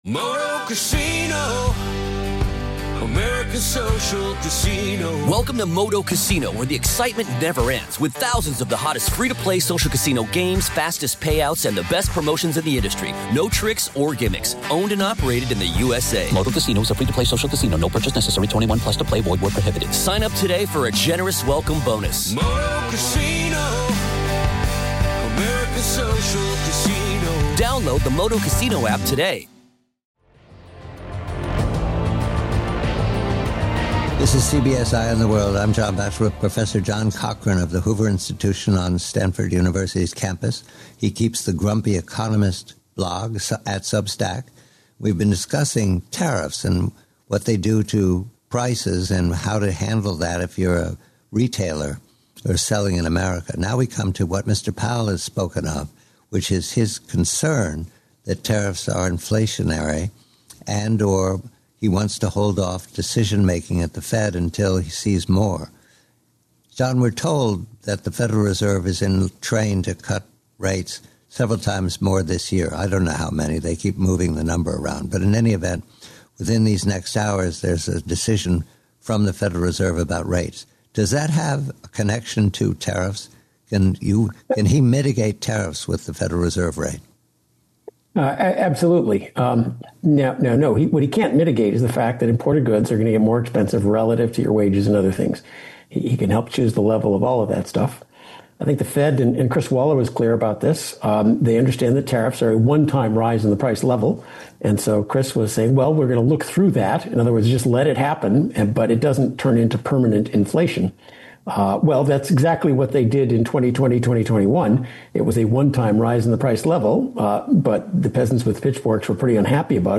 Headliner Embed Embed code See more options Share Facebook X Subscribe Tariffs as a Consumer Tax and the Inflationary Impact Guest: Professor John Cochrane Professor John Cochrane explains that tariffs effectively function as a tax on American consumers and create a temporary bump in inflation throughout the economy.